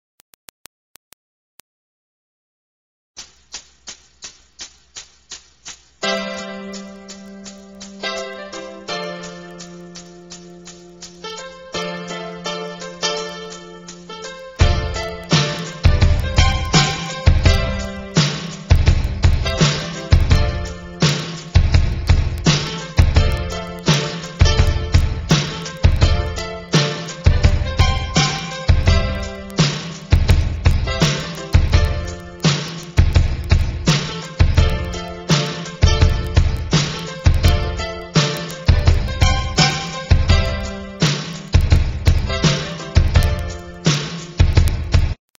HIPHOP, RAP KARAOKE CDs